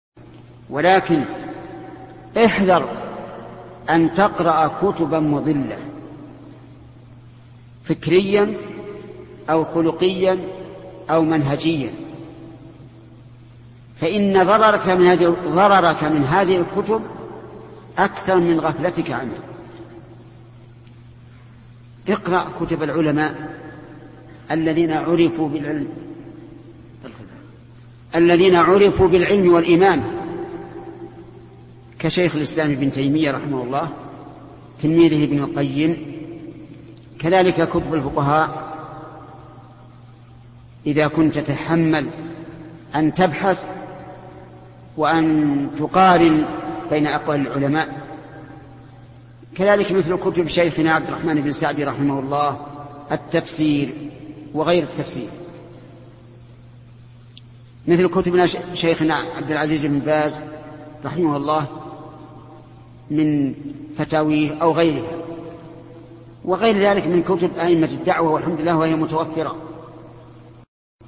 Download audio file Downloaded: 453 Played: 390 Artist: الشيخ ابن عثيمين Title: احذر أن تقرأ كتباً مضلة فكرياً أو خُلُقياً أو منهجياً Album: موقع النهج الواضح Length: 1:11 minutes (319.4 KB) Format: MP3 Mono 22kHz 32Kbps (CBR)